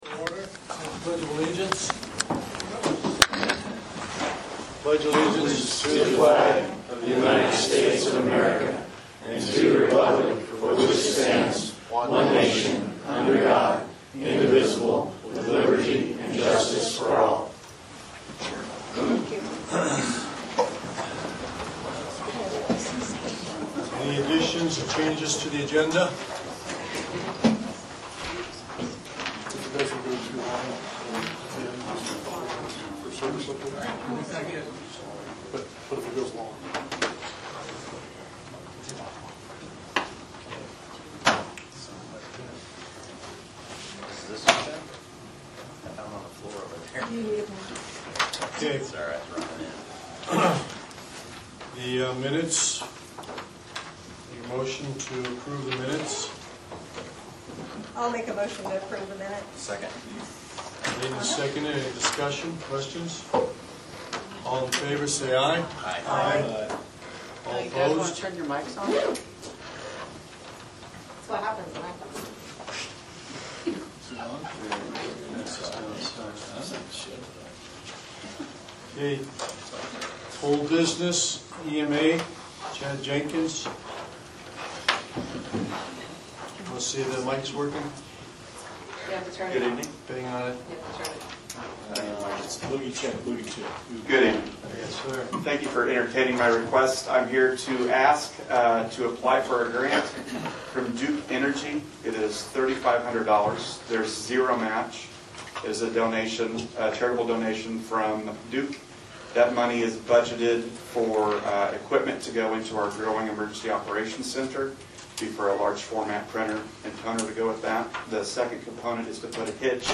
Agenda #3 County Council Meeting July 15, 2024